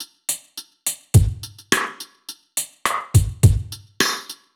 Index of /musicradar/dub-drums-samples/105bpm
Db_DrumKitC_Dry_105-01.wav